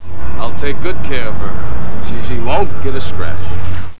Voiced by Billy Dee Williams.